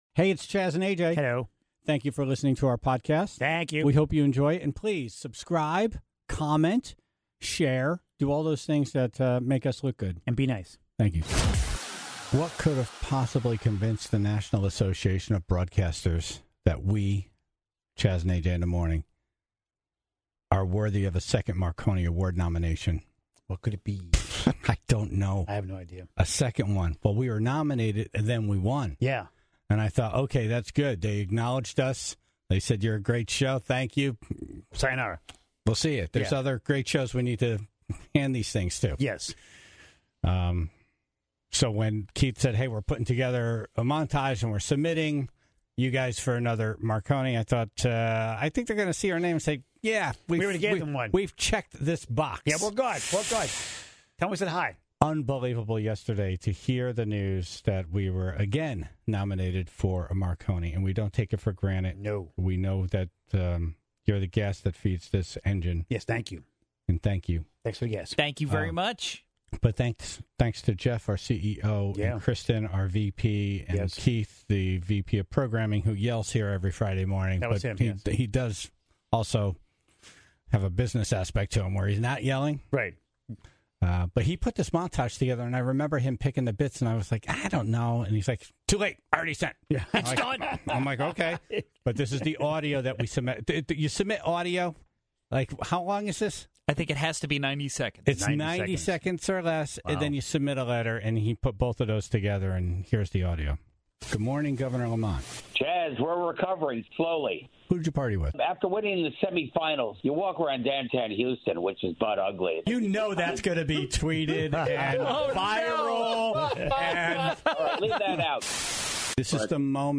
(22:14) Una Dolce Vita from Bridgeport was in studio after submitting the winning bid at an auction.